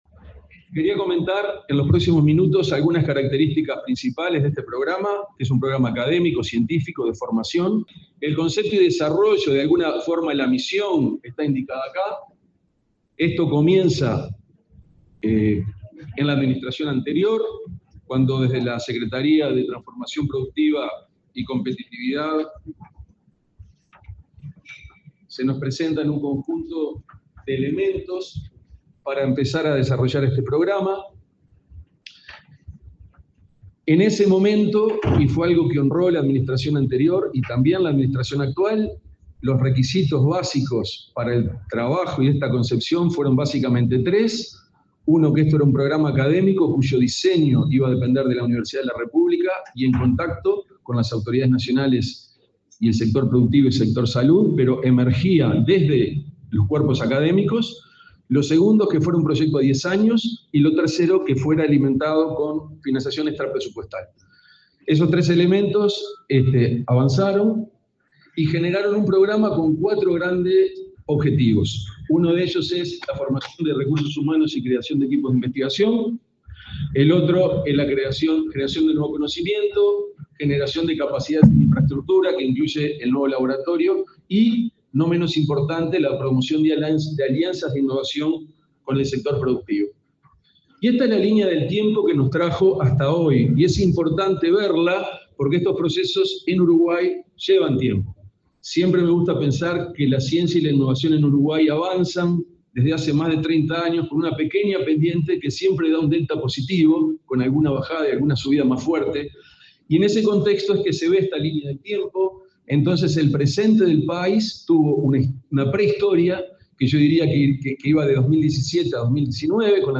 Palabra de autoridades en acto en Facultad de Medicina
Palabra de autoridades en acto en Facultad de Medicina 28/08/2024 Compartir Facebook X Copiar enlace WhatsApp LinkedIn El director científico del Programa de Alimentos y Salud Humana (PAYS) de la Facultad de Medicina de la Udelar, Rafael Radi, y el ministro interino de Ganadería, Ignacio Buffa, explicaron el alcance de ese programa que se inauguró este miércoles 28, con la presencia del presidente Luis Lacalle Pou.